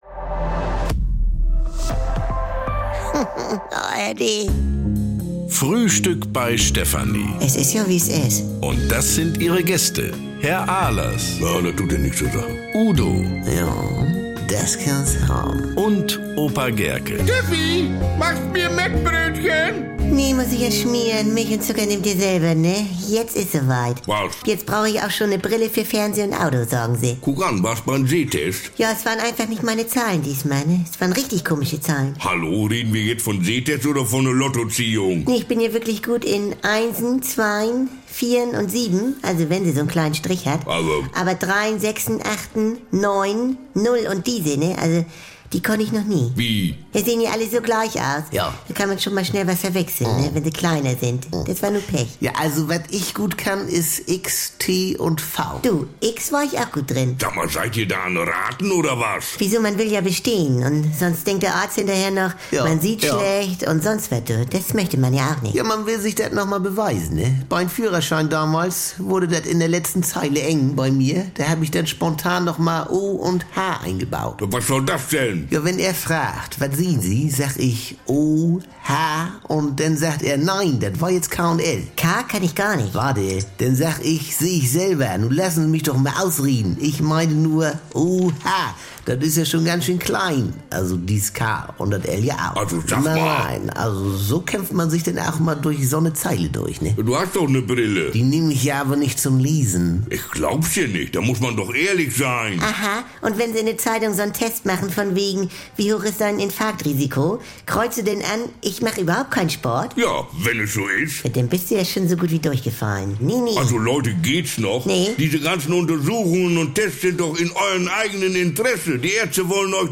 Frisch geschmierte Mettbrötchen, Schnorrer-Tipps, Pyro-Fantasien und brummeliges Gemecker bekommt ihr jeden Tag im Radio oder jederzeit in der ARD Audiothek.